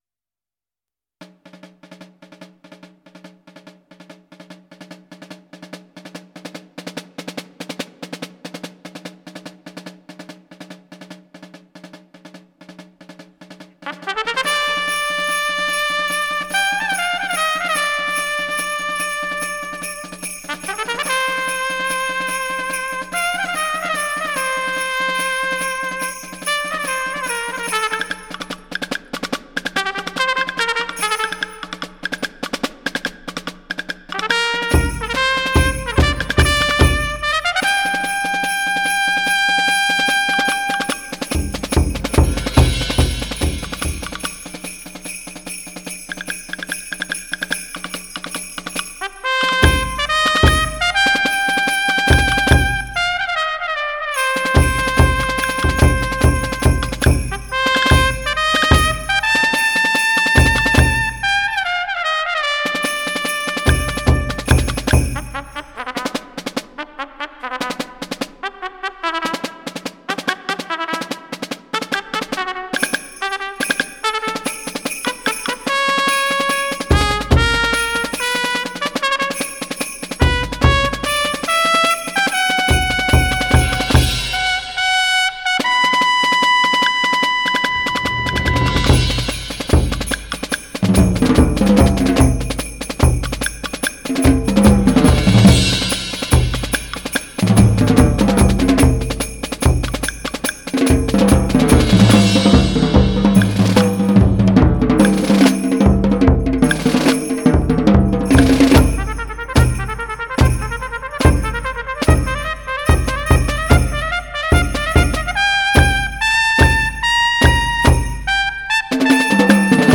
小军鼓和通通鼓逼真地描摹出马蹄的节奏，小号把挎刀和盔甲带到我们眼前。